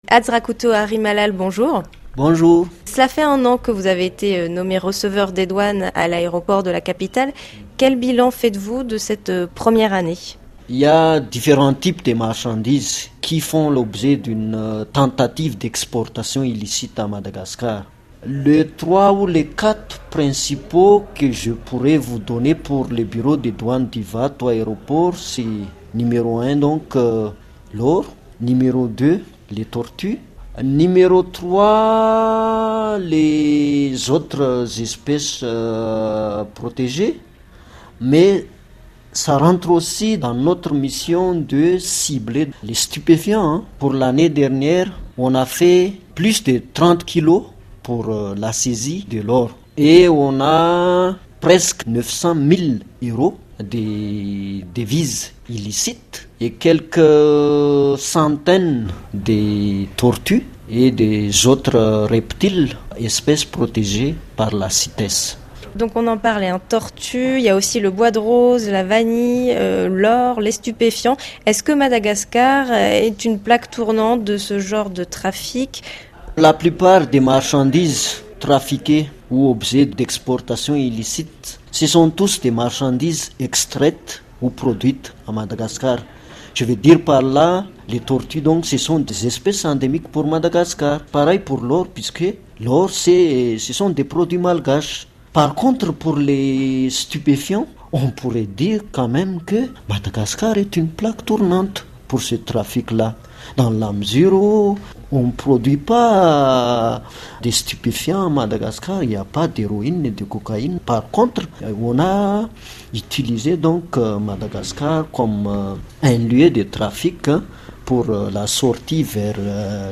Témoignage poignant d’un douanier sur les trafics à l’aéroport d’Ivato